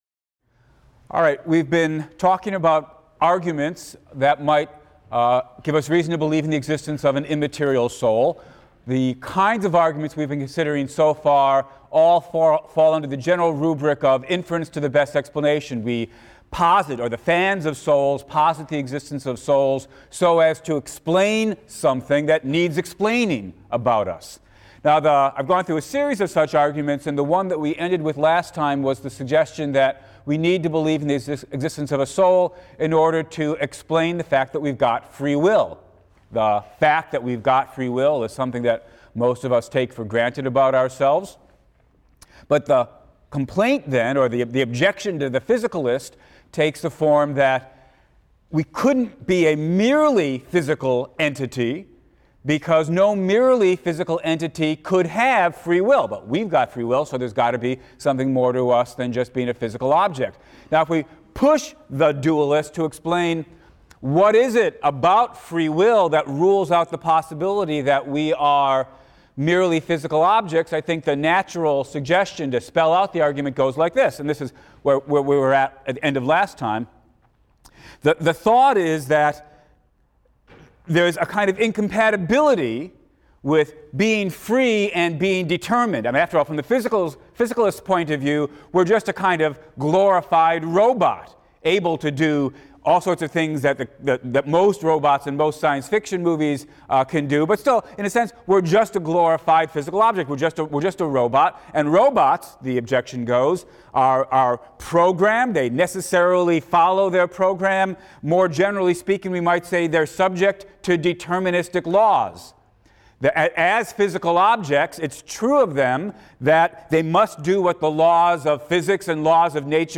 PHIL 176 - Lecture 5 - Arguments for the Existence of the Soul, Part III: Free Will and Near-Death Experiences | Open Yale Courses